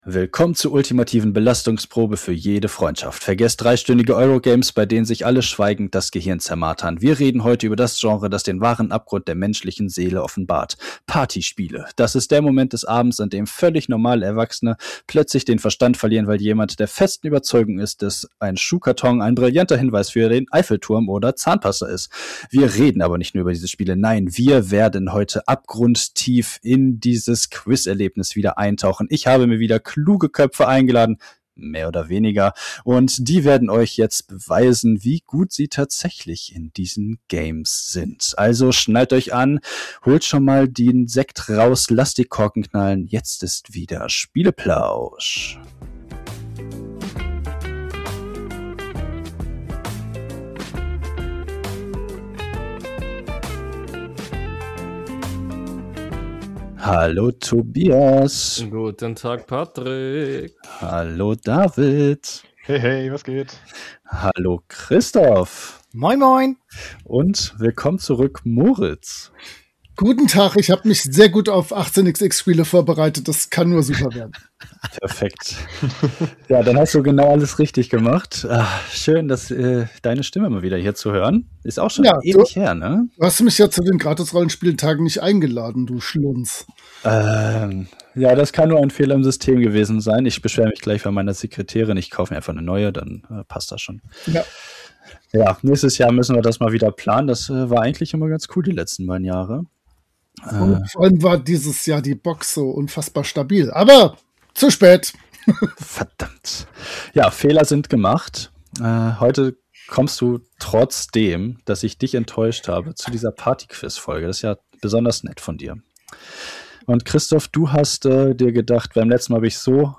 Wir Quizzen wieder um die Wette. Wer kennt sich mit Partyspielen am besten aus?